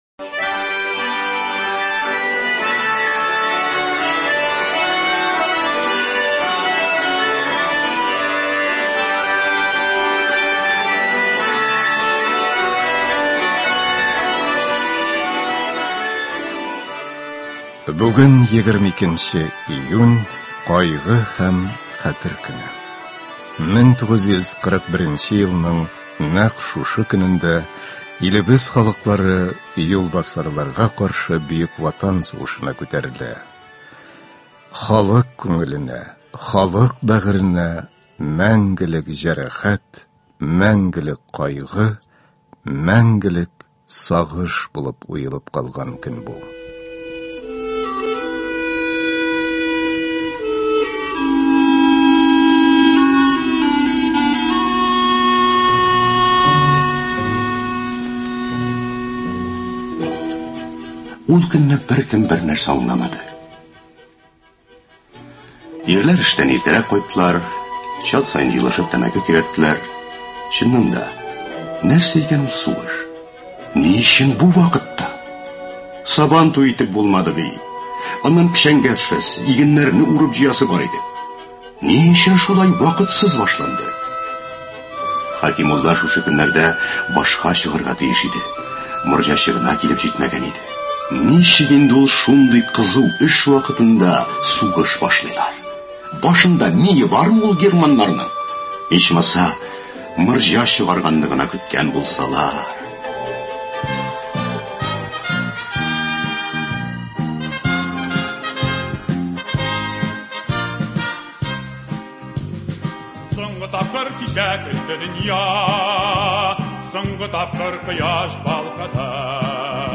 Хәтәр һәм кайгы көненә карата концерт (22.06.22)